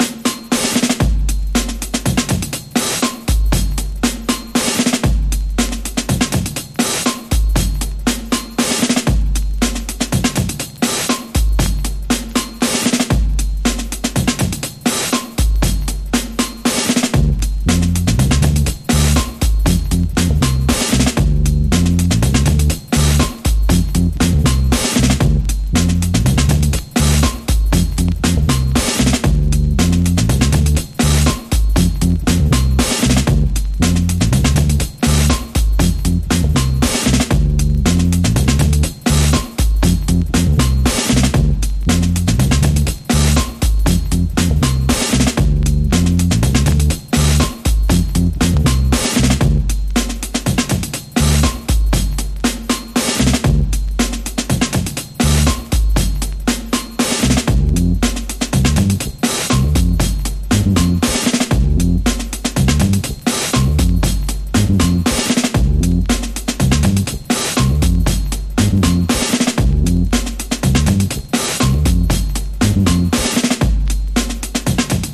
ディープ・ファンク＆ドラム・ブレイクを使った強烈なトラック！
ディープ・ファンクの声ネタやドラム・ブレイク、スクラッチが次々飛び出す、強力なカットアップ・ブレイクビーツです。